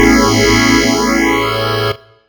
Gospel Chord Ab.wav